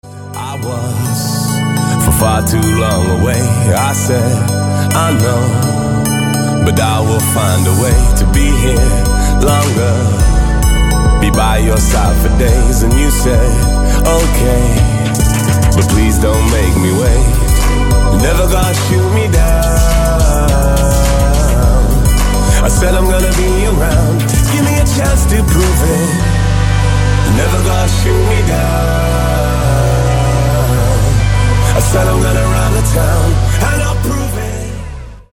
• Качество: 224, Stereo
мужской вокал
dance
Electronic
club
vocal